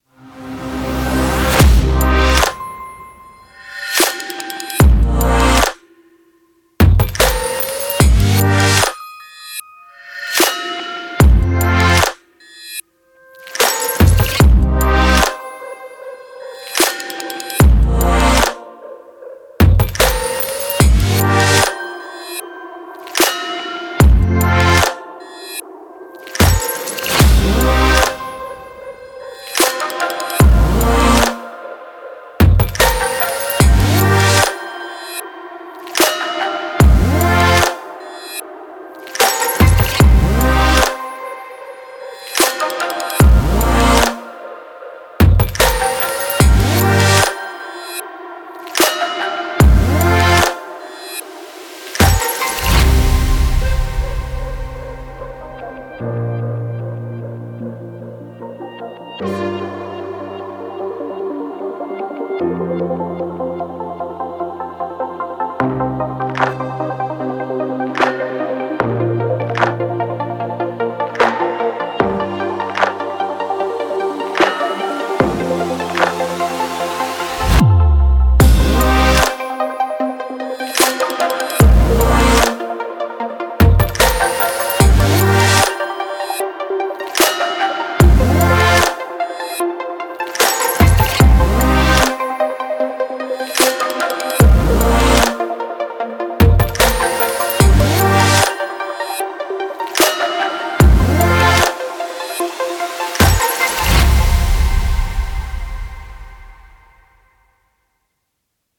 Genre: electronica.